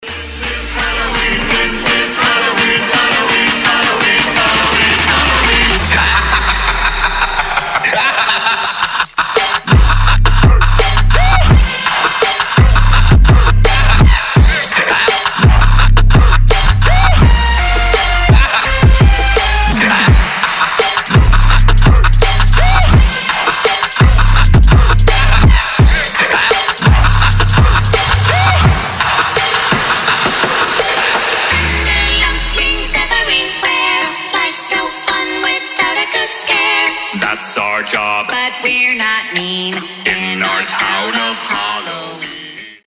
• Качество: 320, Stereo
громкие
забавный голос
звук разбитого стекла
злой смех